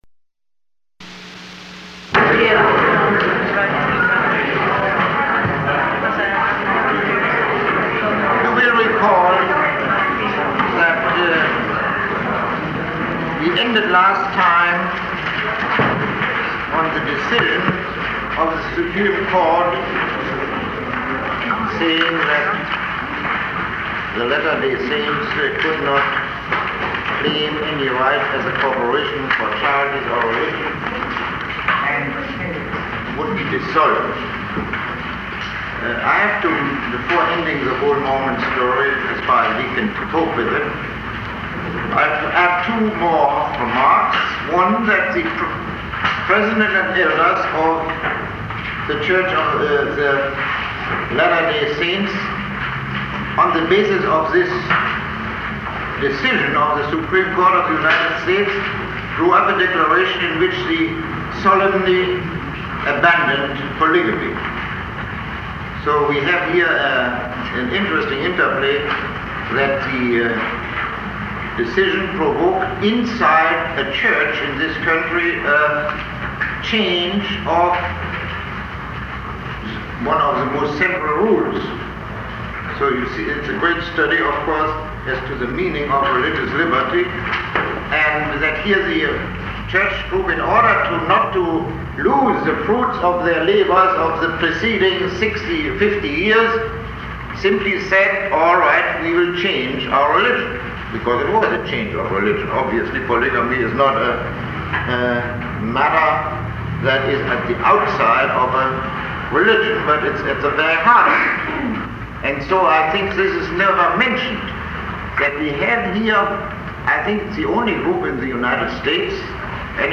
Lecture 28